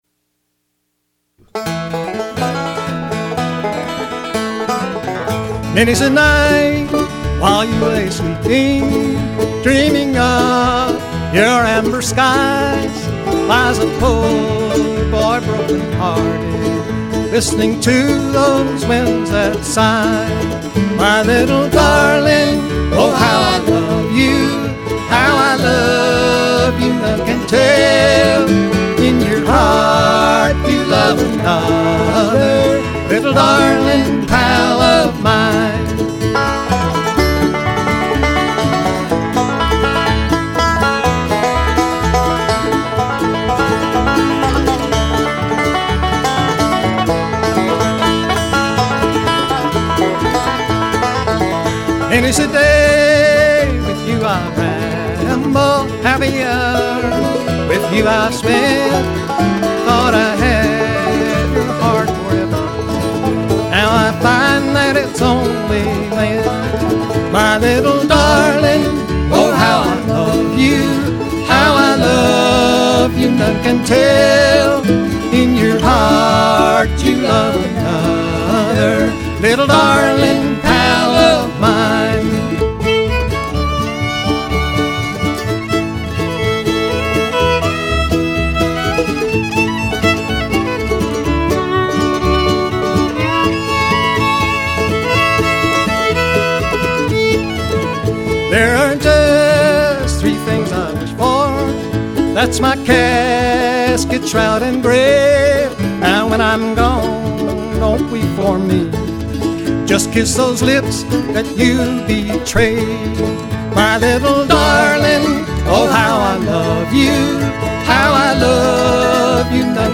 Audio by genre bluegrass | Freight & Salvage